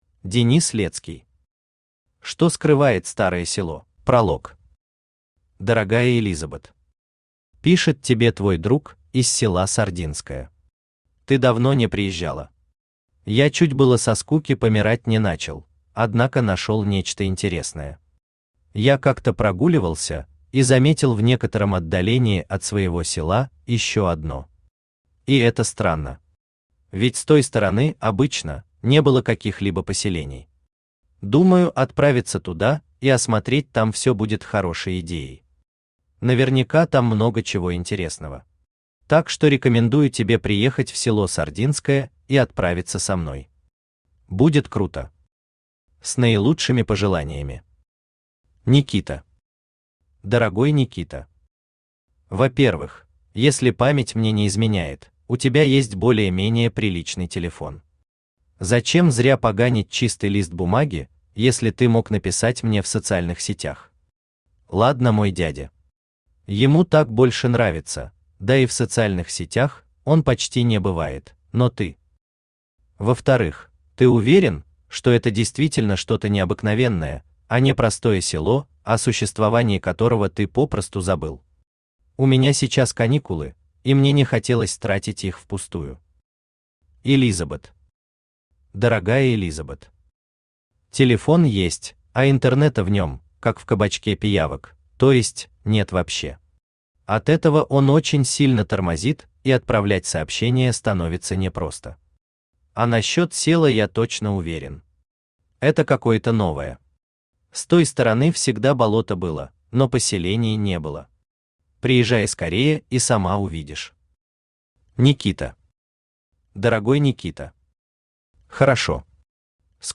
Аудиокнига Что скрывает старое село?